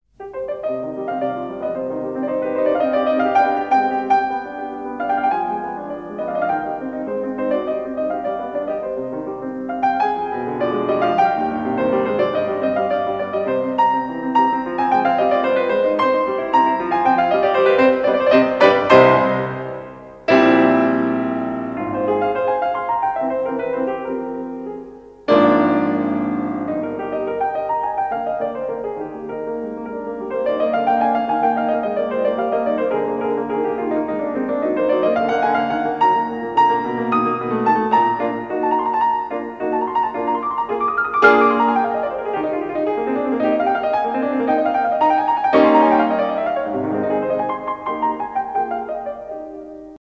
классические
пианино , инструментальные